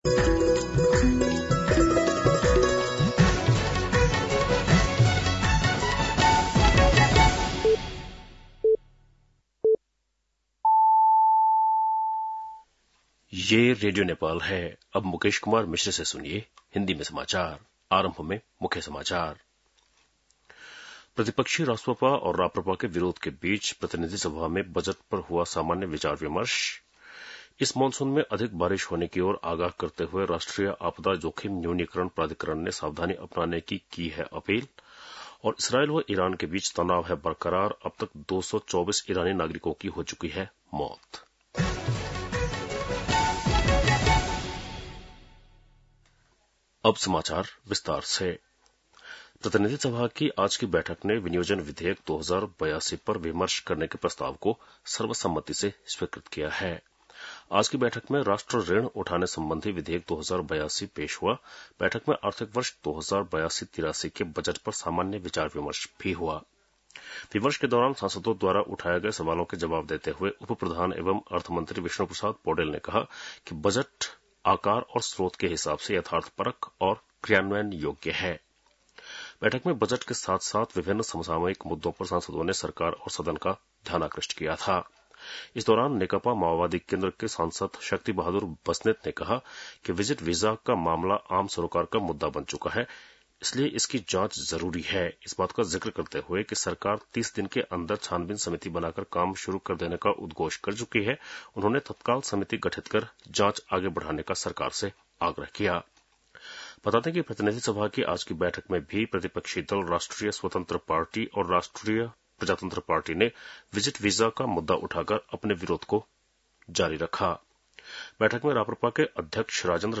बेलुकी १० बजेको हिन्दी समाचार : २ असार , २०८२
10-PM-Hindi-NEWS-3-02.mp3